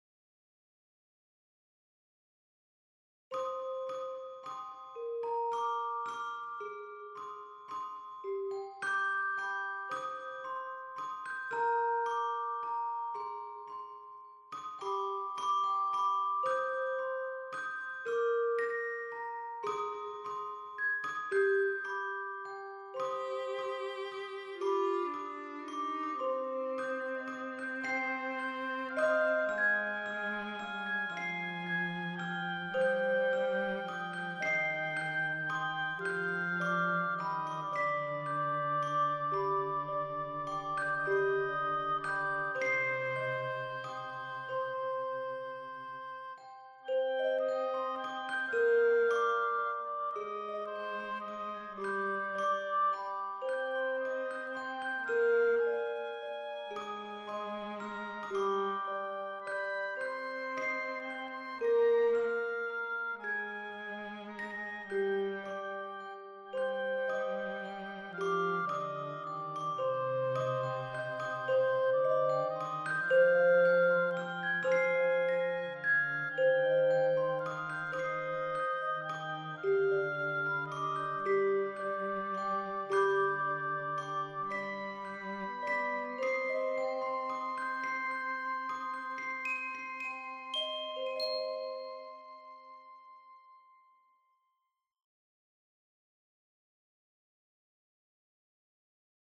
描述：儿童音乐|悲伤
Tag: 弦乐器